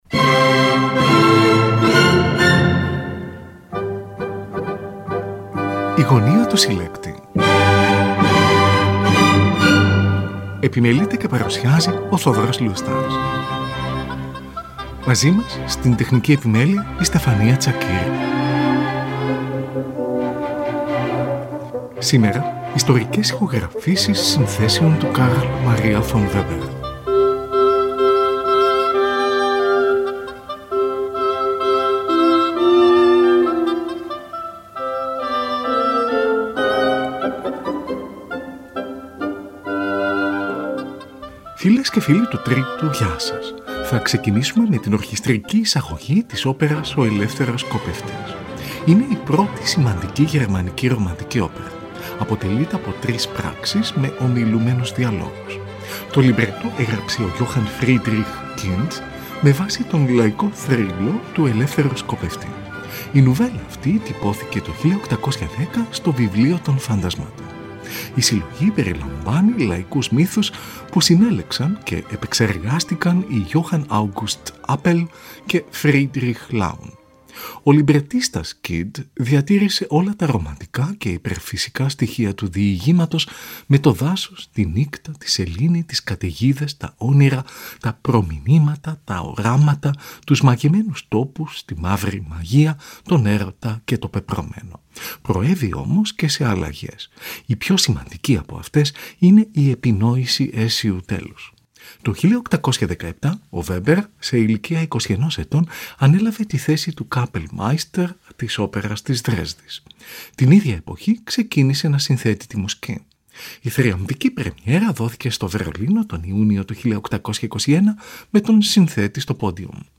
Ιστορικές Ηχογραφήσεις Έργων του Carl Maria von Weber